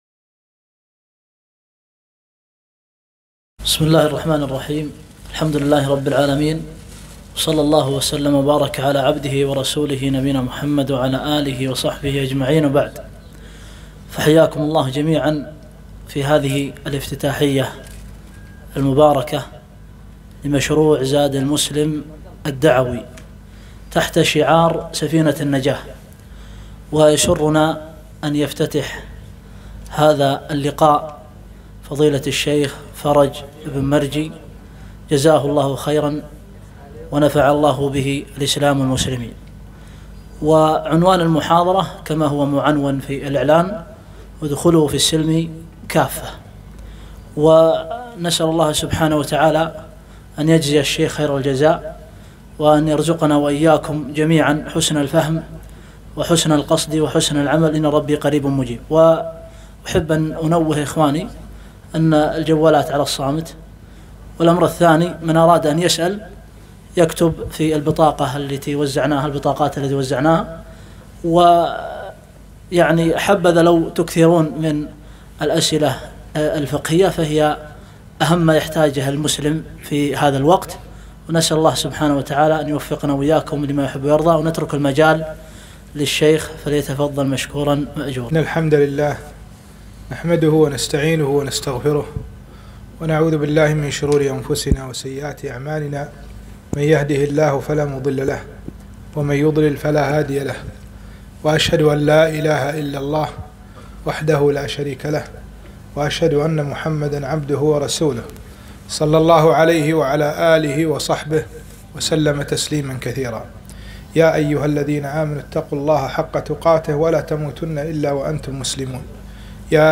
محاضرة - ( ادخلوا في السلم كافة )